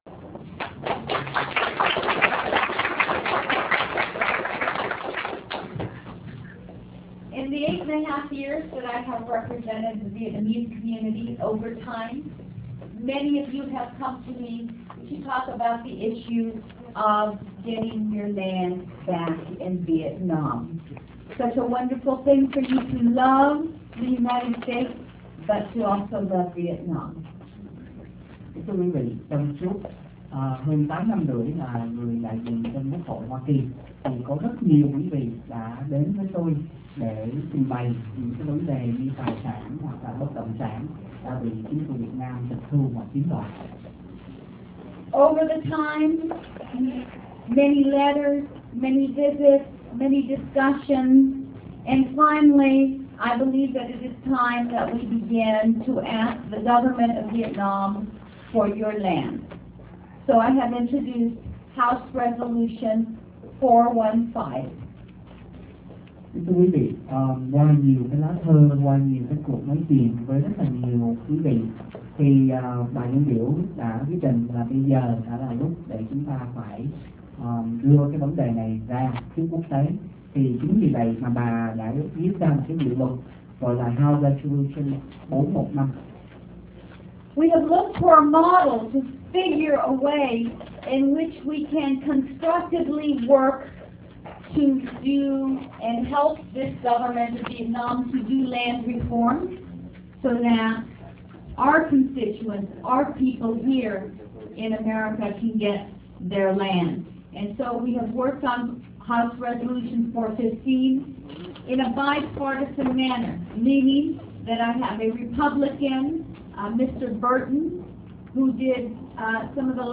(phi�n dịch)